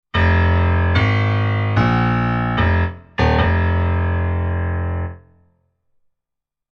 Intense-piano-phrase.mp3